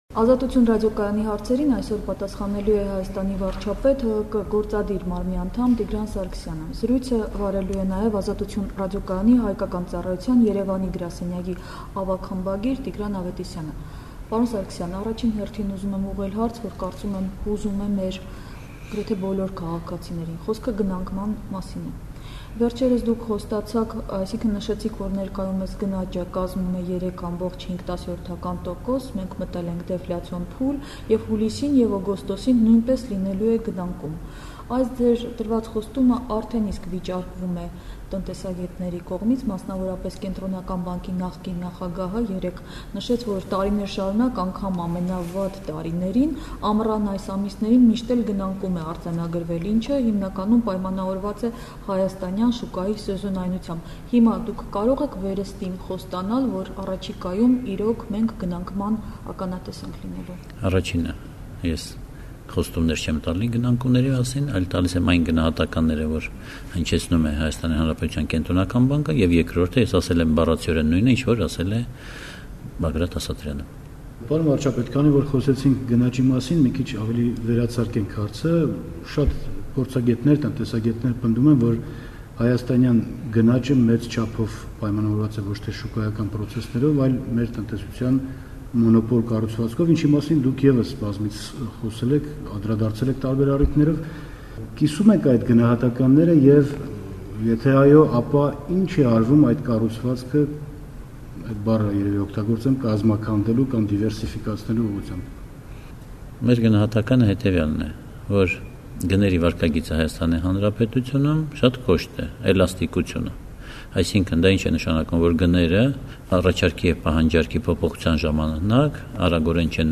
Հարցազրույց Տիգրան Սարգսյանի հետ
«Ազատություն» ռադիոկայանին տված բացառիկ հարցազրույցում վարչապետ Տիգրան Սարգսյանը խոսում է Հայաստանի տնտեսական եւ քաղաքական վիճակի մասին։